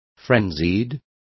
Complete with pronunciation of the translation of frenzied.